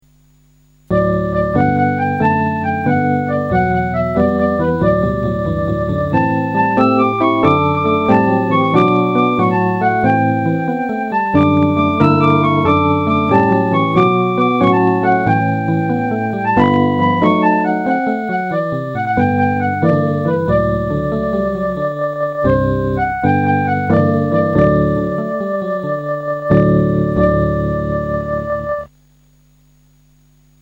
Listen to some LucyTuned instrumental Vocalizer mp3 renditions:
Irish Lullaby - LucyTuned song